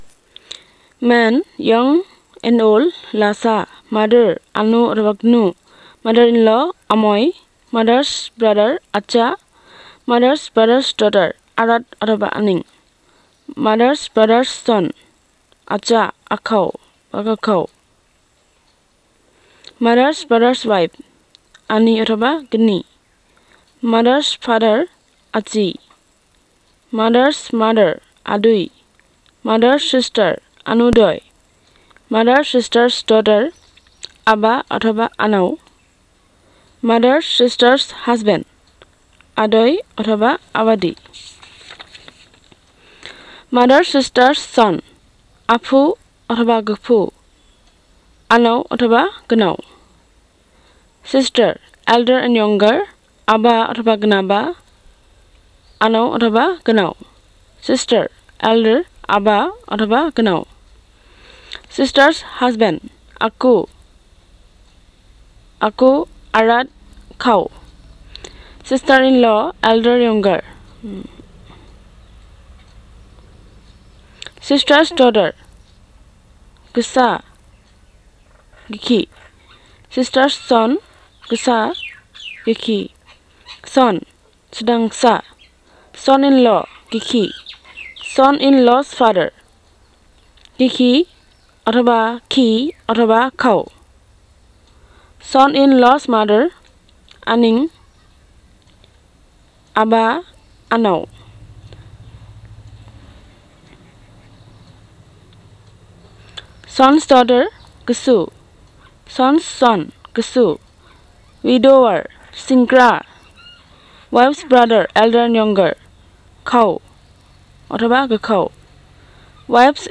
Elicitation of words about kinship terms